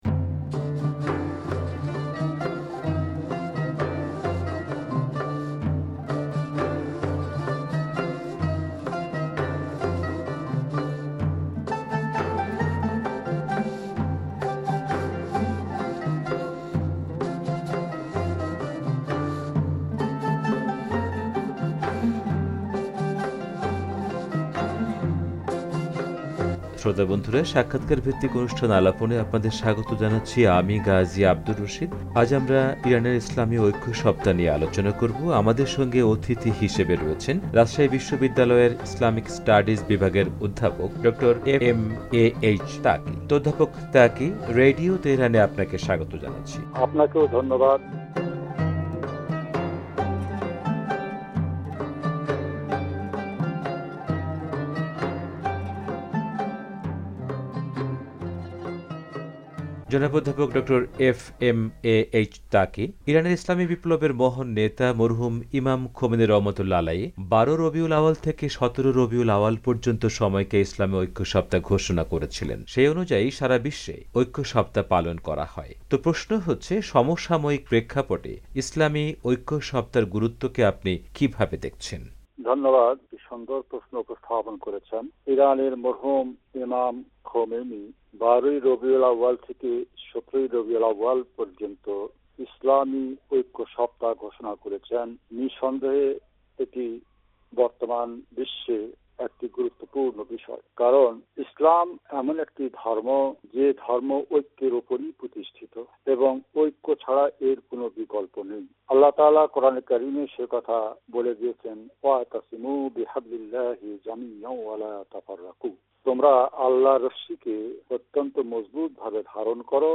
পুরো সাক্ষাৎকারটি উপস্থাপন করা হলো।